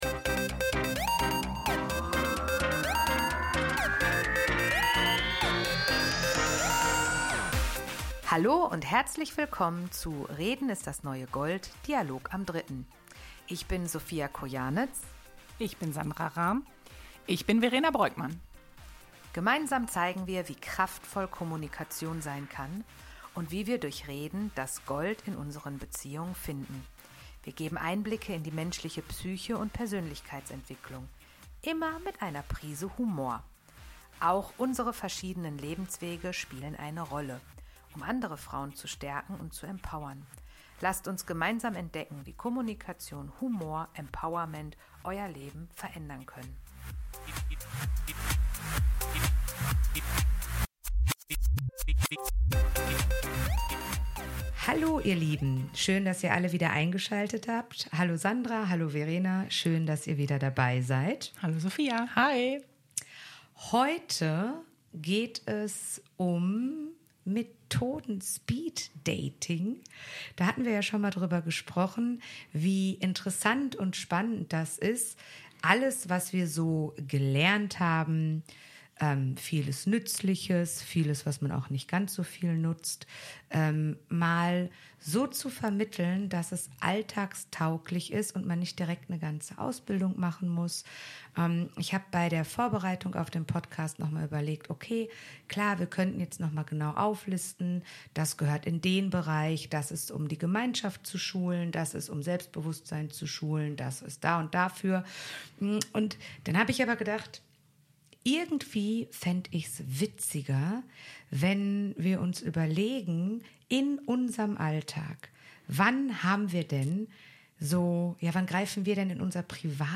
In einem lebendigen, aber fundierten Gespräch gehen sie der Frage nach, welche Methoden in welchen Situationen wirklich hilfreich sind – sei es im Beruf, im Team oder im privaten Umfeld. Anhand konkreter Beispiele zeigen die drei Gesprächspartnerinnen, wie Techniken wie aktives Zuhören, gewaltfreie Kommunikation oder systemisches Fragen ihre Wirkung entfalten können – und wo ihre Grenzen liegen.